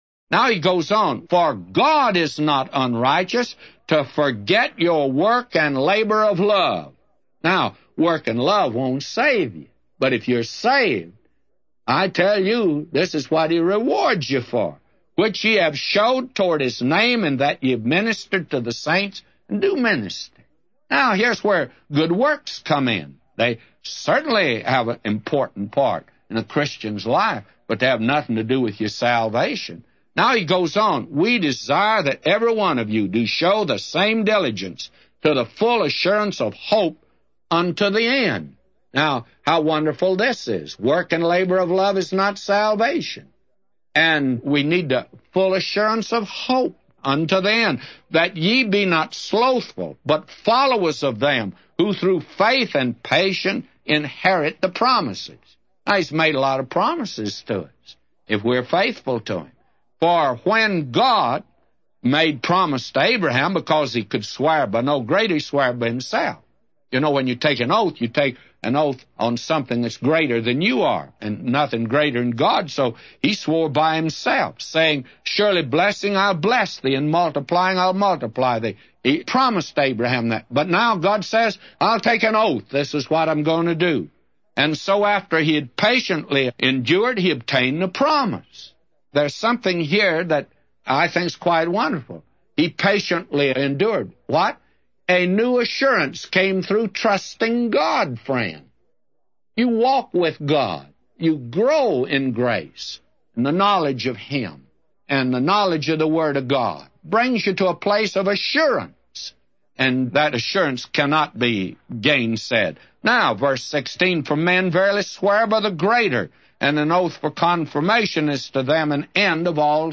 A Commentary By J Vernon MCgee For Hebrews 6:10-999